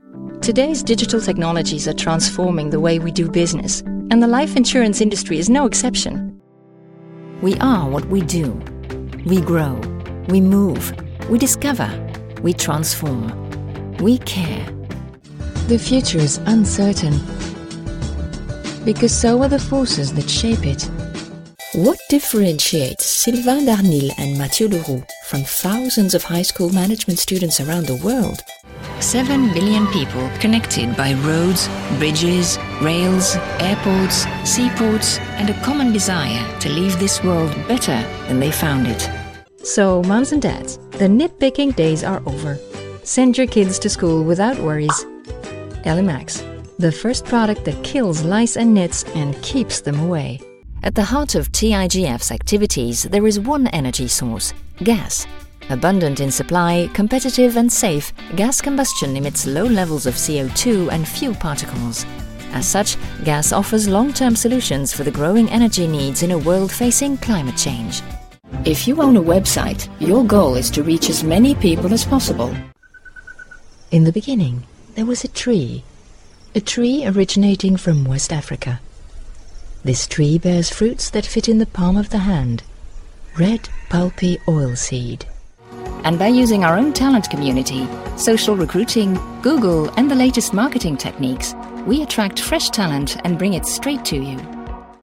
ENGLISH Narration - international accent
I offer multiple voice profiles: expressive and friendly, intelligent and confident, or luxurious and sophisticated.